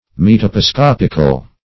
Search Result for " metoposcopical" : The Collaborative International Dictionary of English v.0.48: Metoposcopic \Met`o*po*scop"ic\, Metoposcopical \Met`o*po*scop"ic*al\, a. Of or relating to metoposcopy.